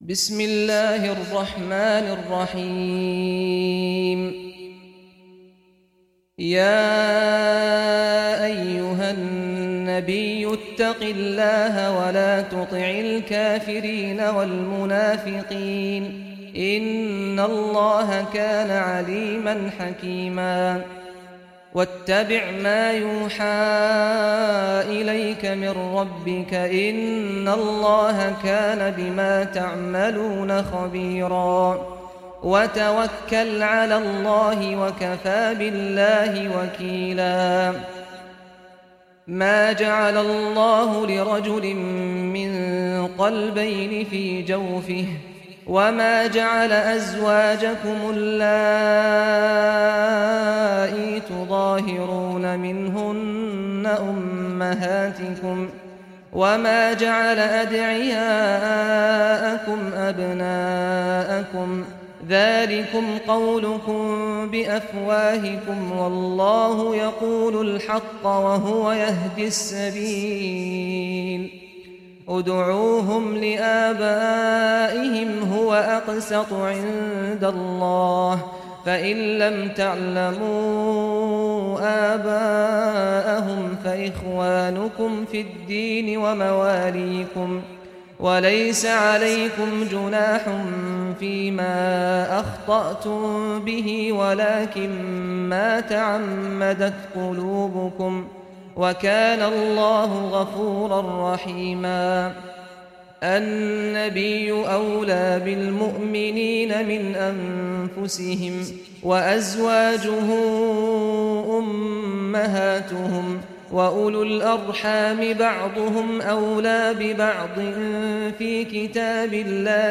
Surah Al-Ahzab Recitation by Sheikh Saad Ghamdi
Surah Al-Ahzab, listen or play online mp3 tilawat / recitation in Arabic in the beautiful voice of Imam Sheikh Saad al Ghamdi.